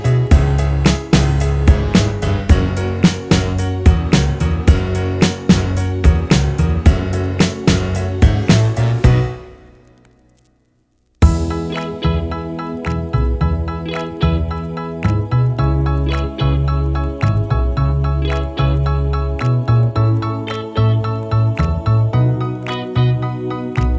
no Backing Vocals Duets 3:14 Buy £1.50